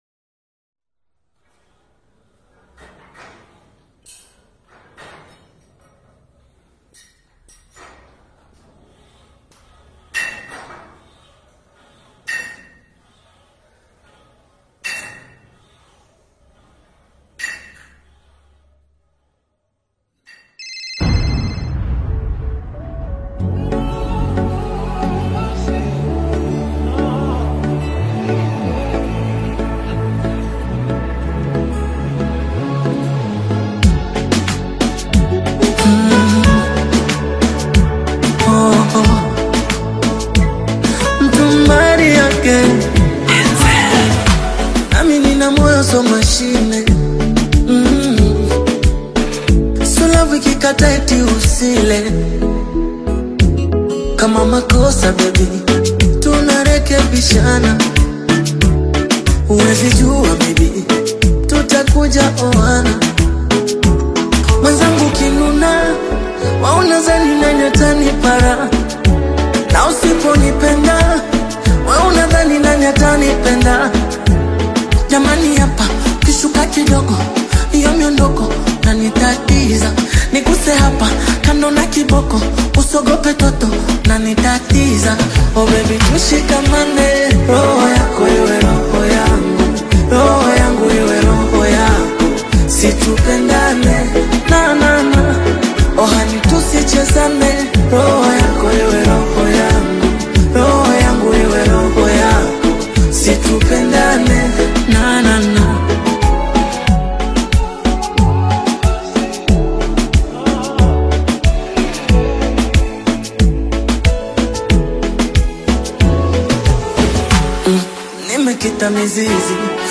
heartfelt Afro-Pop
melodic Afro-Pop rhythms
creating a romantic anthem